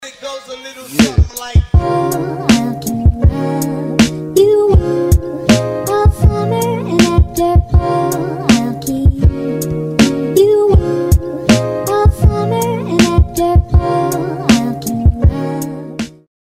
Бесплатная музыка для интро